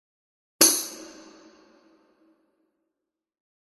Звуки шаманского бубна
На этой странице собраны звуки шаманского бубна – от ритмичных ударов до глубоких вибраций.
Удар по шаманскому бубну